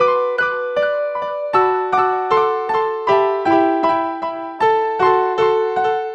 Track 07 - Piano 02.wav